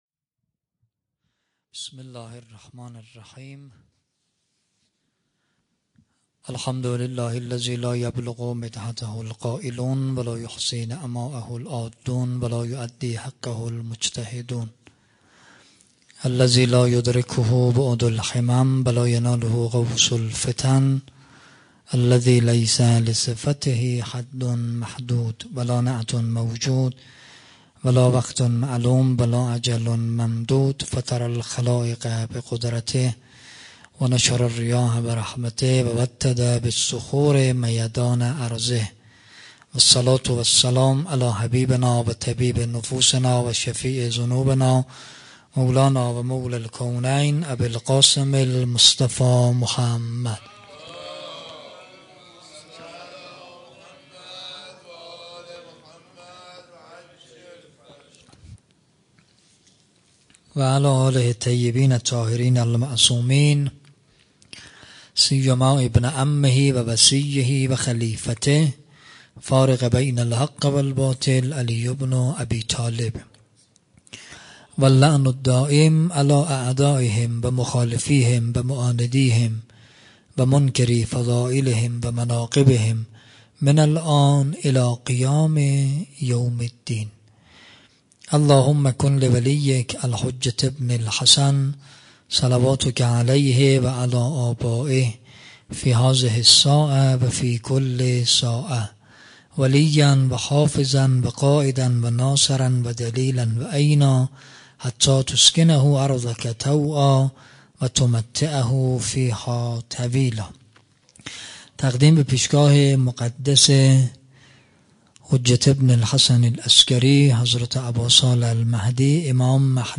شب شانزدهم رمضان 96 - ریحانة النبی - سخنرانی